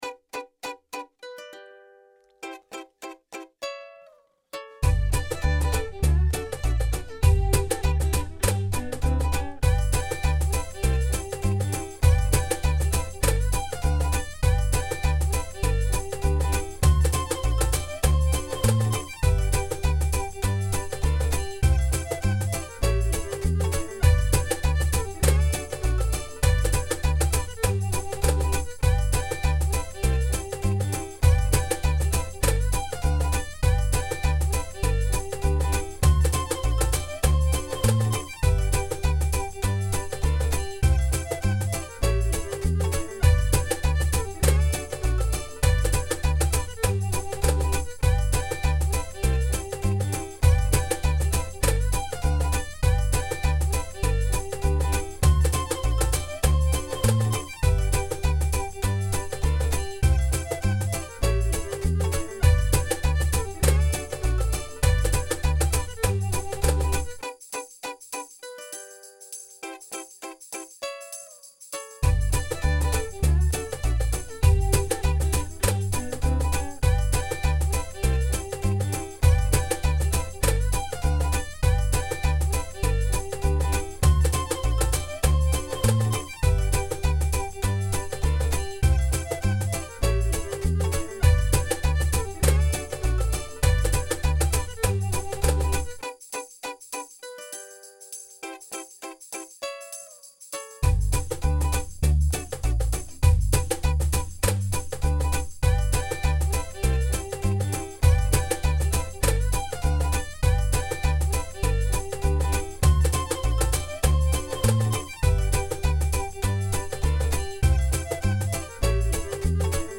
Und damit ihr eine Band für den Hintergrund (und für das Live-Spiel des Solos – ob mit Ukulele und/oder mit Löffeln) habt, liegt
hier noch ein Playback.